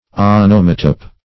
onomatope - definition of onomatope - synonyms, pronunciation, spelling from Free Dictionary
Search Result for " onomatope" : The Collaborative International Dictionary of English v.0.48: Onomatope \O*nom"a*tope\, n. [See Onomatopoeia .] An imitative word; an onomatopoetic word.